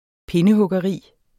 Udtale [ ˈpenəhɔgʌˌʁiˀ ]